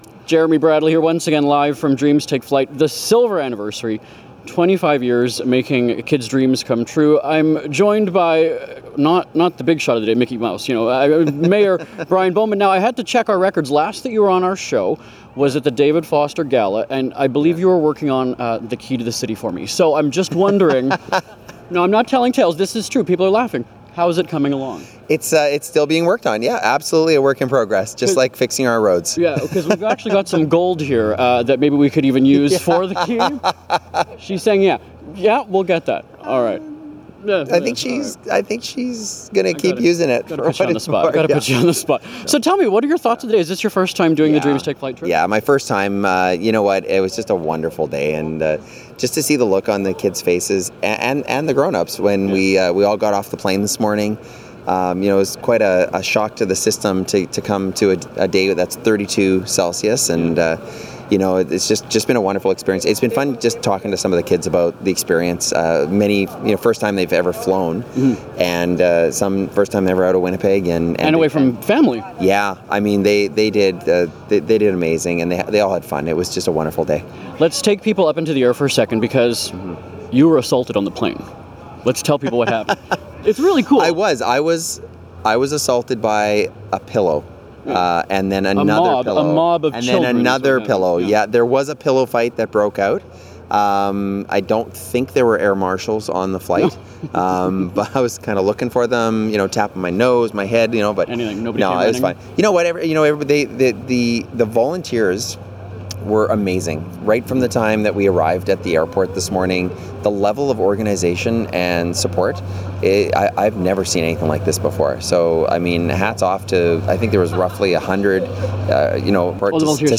Also, I had a chance to chat with Mayor Bowman as the day at Disney World came to an end.